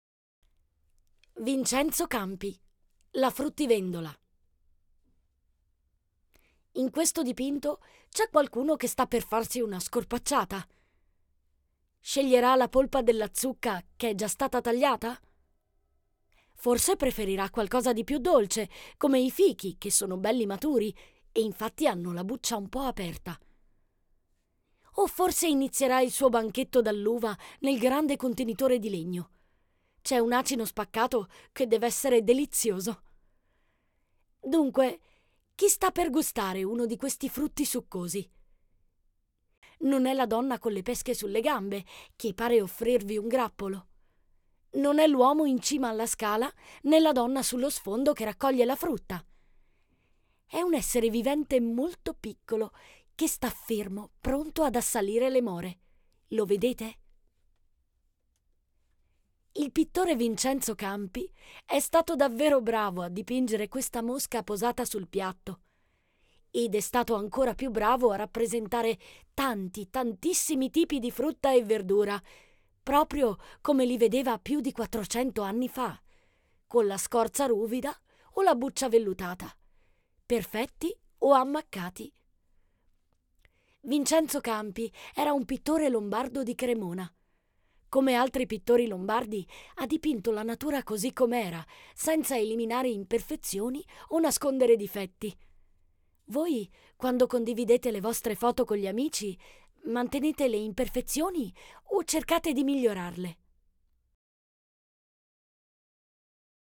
Audioguida "Brera in famiglia"